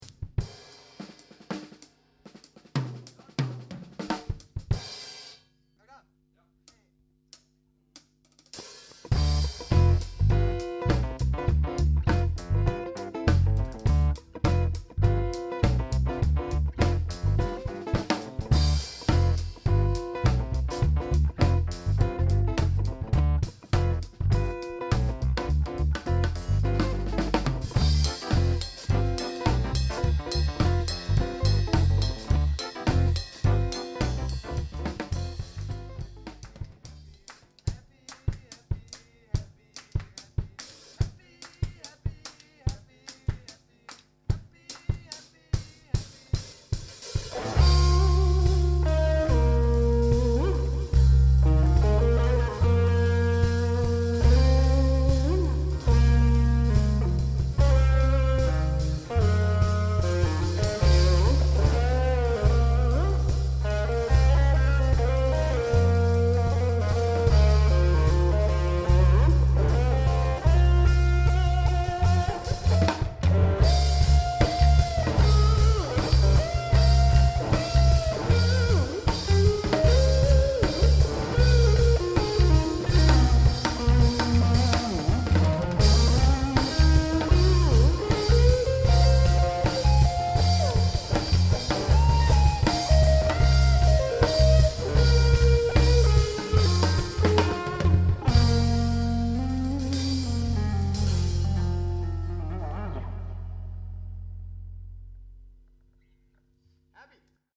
Med saxofon og funky bas bliver det stensikkert en fest!
• Allround Partyband
• Coverband
• Instrumentalt indtryk fra øveren